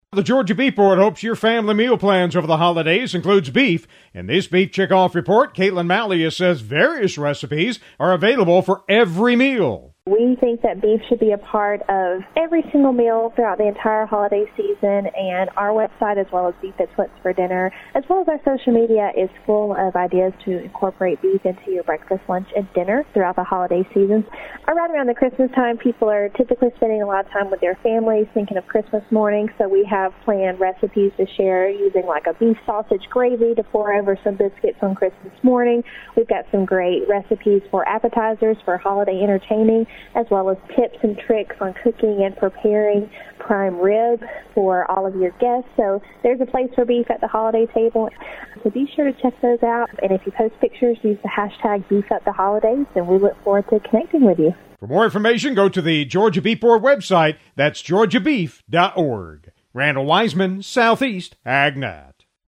The Georgia Beef Board hopes your family meal plans over the holidays includes beef. In this Beef Checkoff report